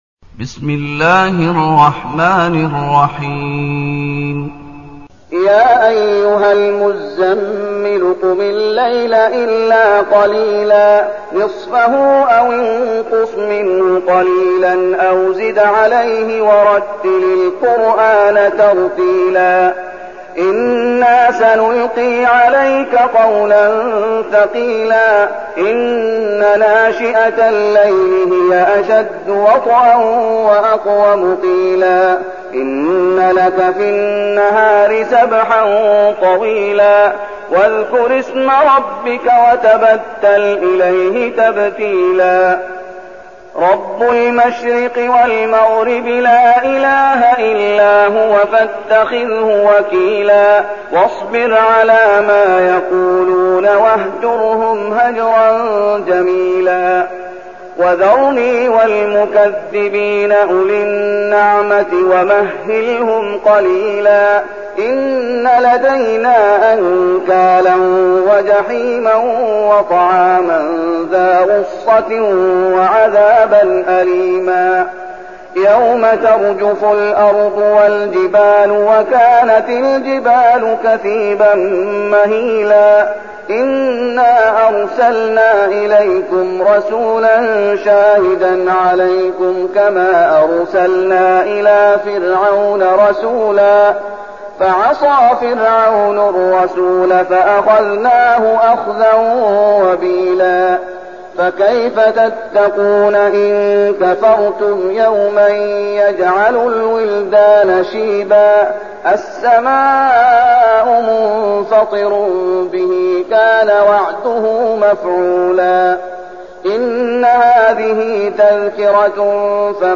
المكان: المسجد النبوي الشيخ: فضيلة الشيخ محمد أيوب فضيلة الشيخ محمد أيوب المزمل